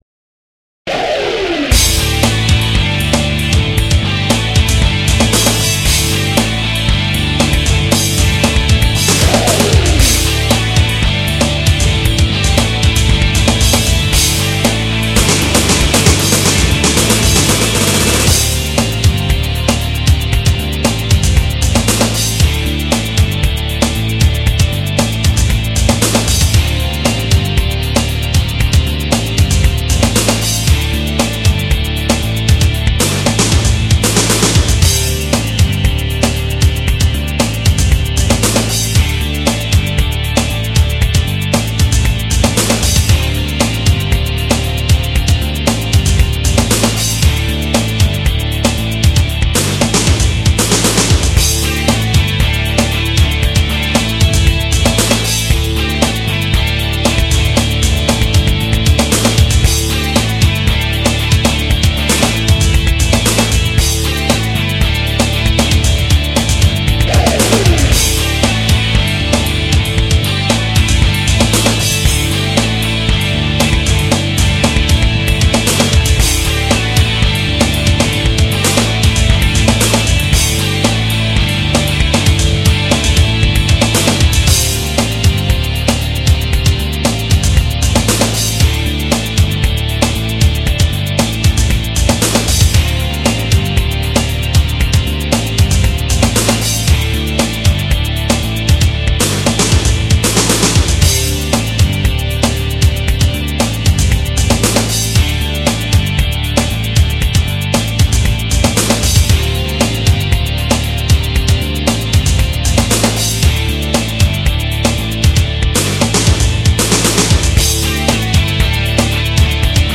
→Rock← Rock調 インスト。